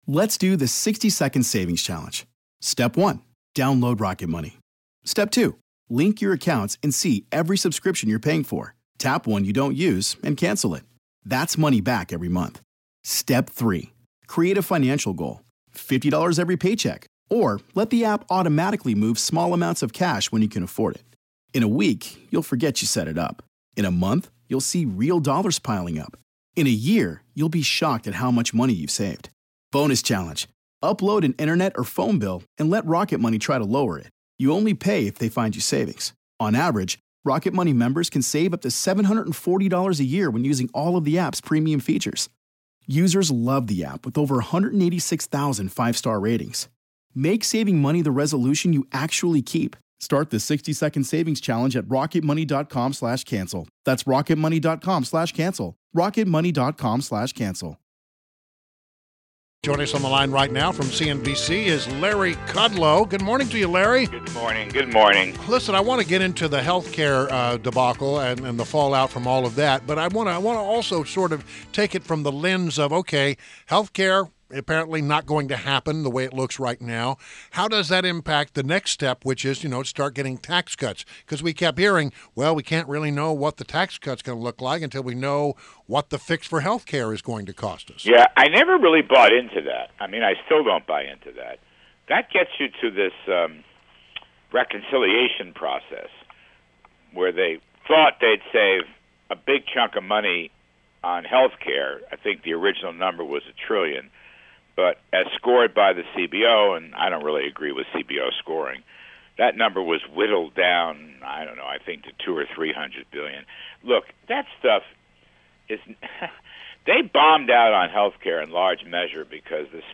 WMAL Interview - Larry Kudlow - 3.28.17